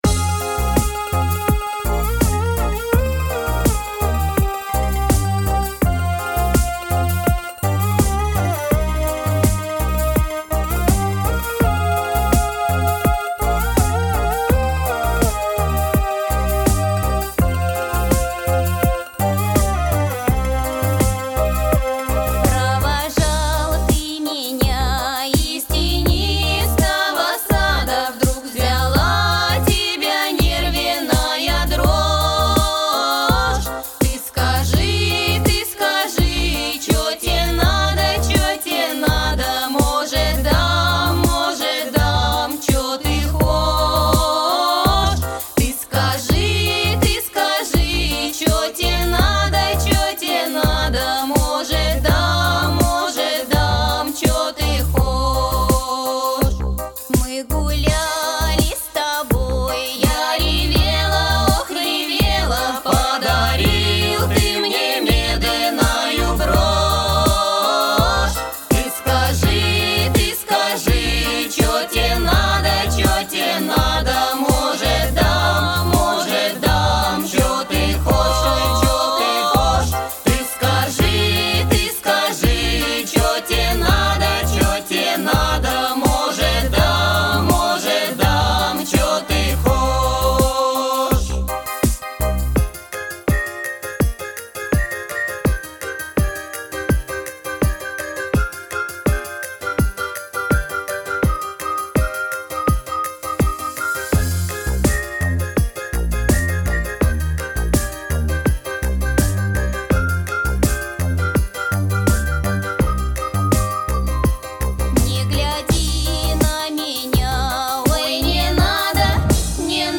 русская народная музыка